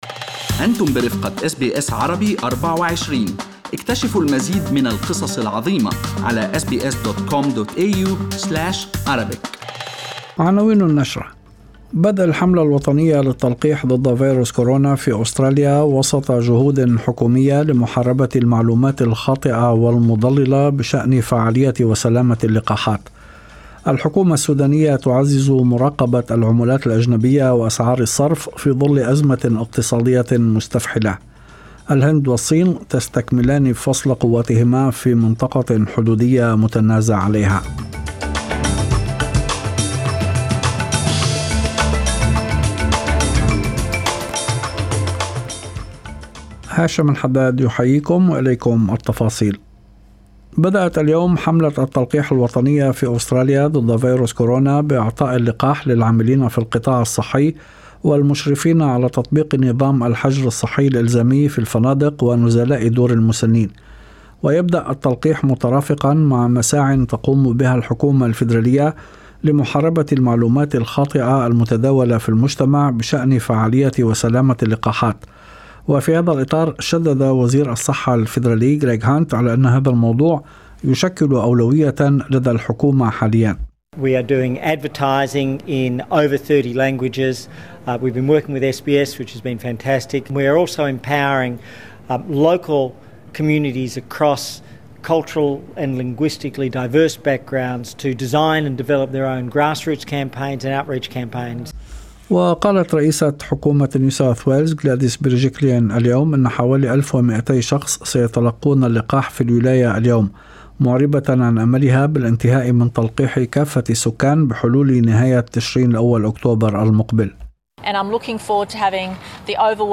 نشرة أخبار المساء 22/02/2021